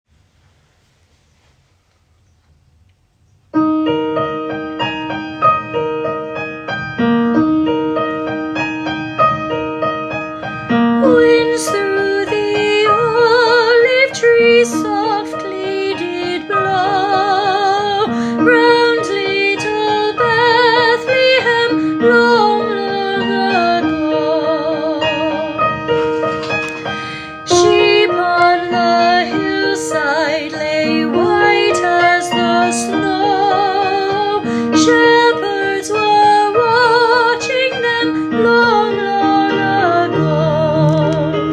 MELODY
Olive-trees-melody.m4a